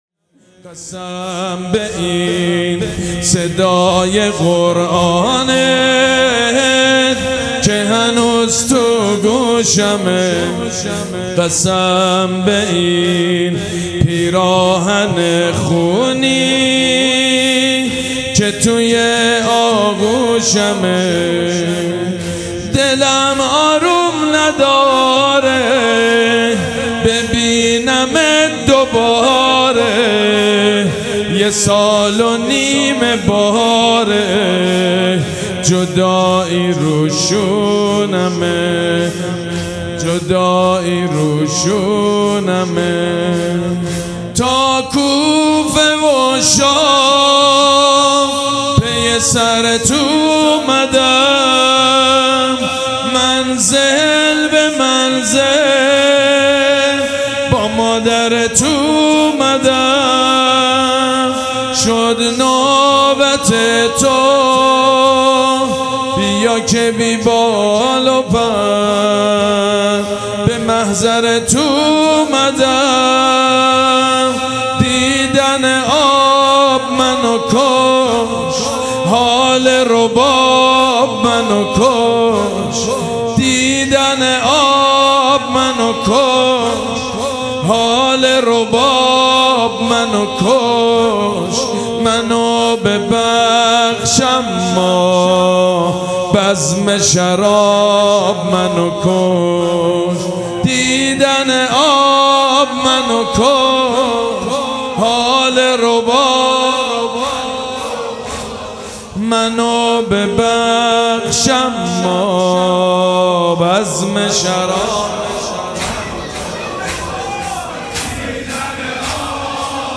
مراسم عزاداری شام شهادت حضرت زینب(س)
حسینیه ریحانة‌الحسین (س)
روضه
مداح